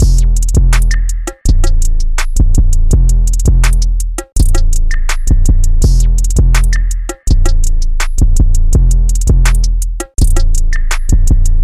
果汁大鼓
标签： 165 bpm Trap Loops Drum Loops 1.96 MB wav Key : E
声道立体声